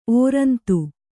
♪ ōrantu